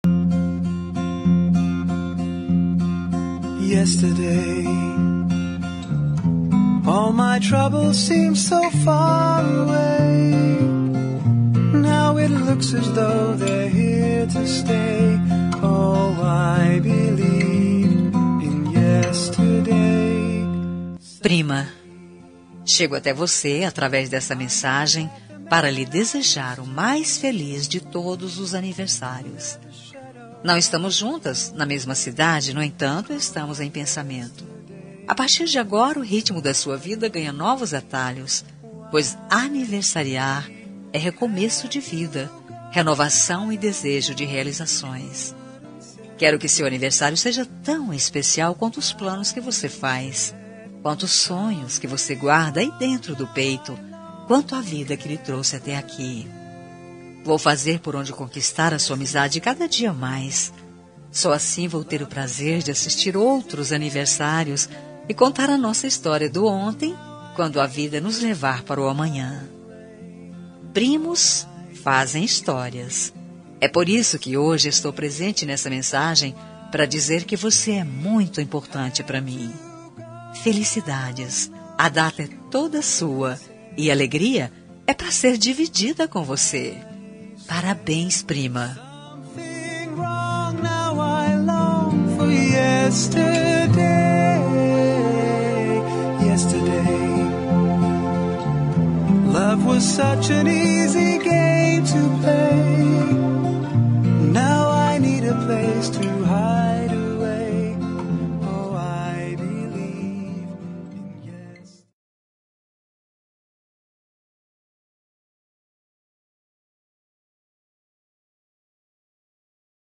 Aniversário de Prima – Voz Feminina – Cód: 042808